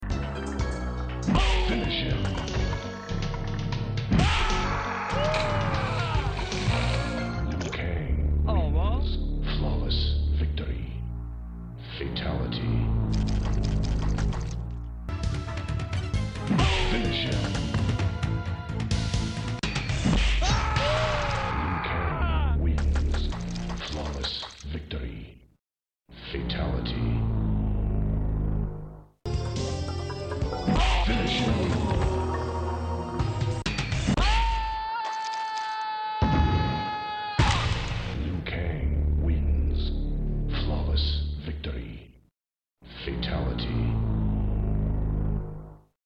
Mortal Kombat II 1993 Arcade Sound Effects Free Download